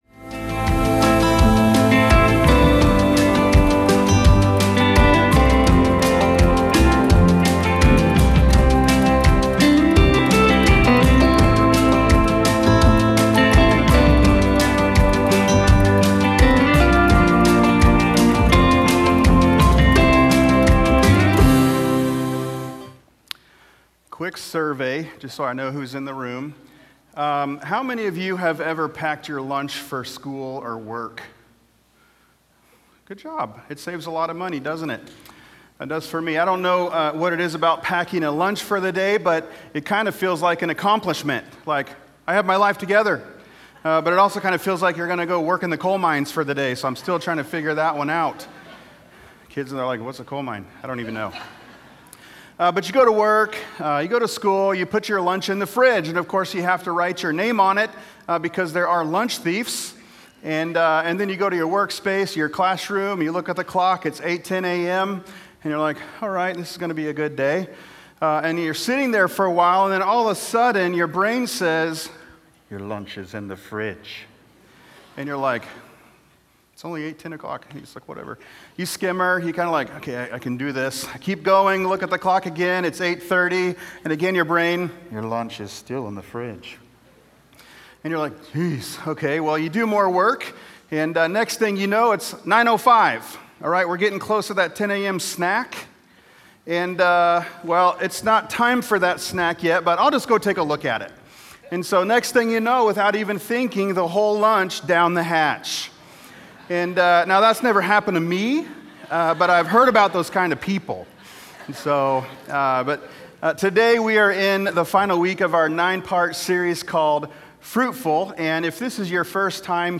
Sunday Sermons FruitFULL, Week 9: "Self Control" Mar 22 2026 | 00:36:30 1x 00:00 / 00:36:30 Subscribe Share Apple Podcasts Spotify Overcast RSS Feed Share Link Embed